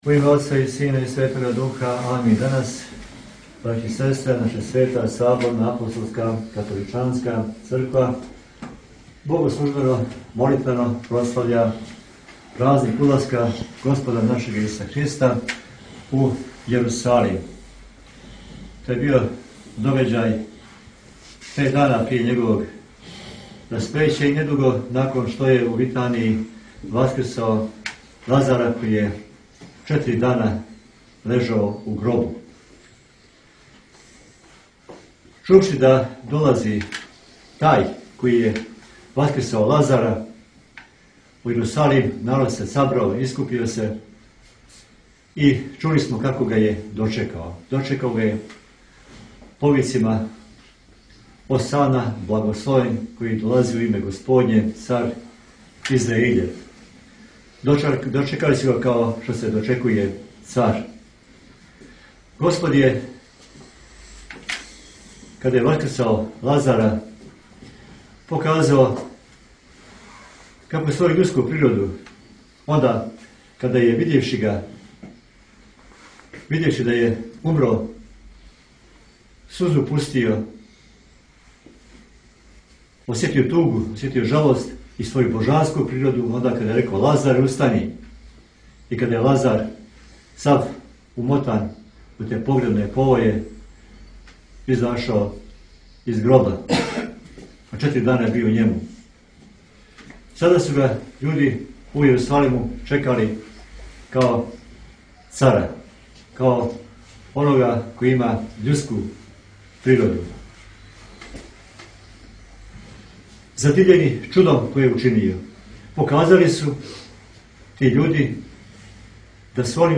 Почетна » Бесједе » Празник Цвијети прослављен у цркви Свих Светих на Великом Пијеску
Празник Цвијети прослављен у цркви Свих Светих на Великом Пијеску